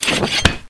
ArmorSnd.ogg